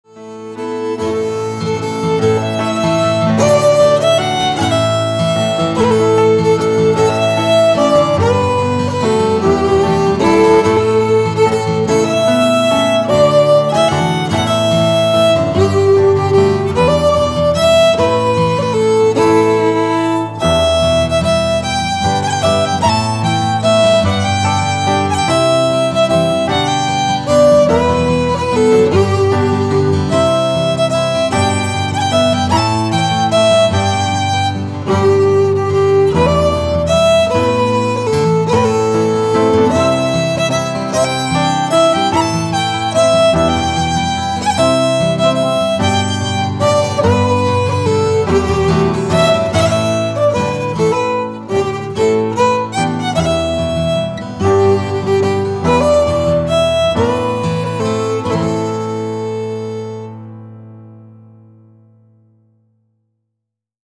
Lots of variety between listening and dance music.